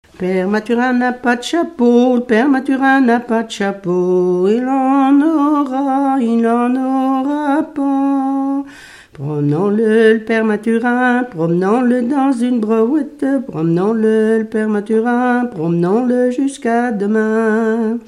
Genre énumérative
Enquête Arexcpo en Vendée-C.C. Yonnais
Pièce musicale inédite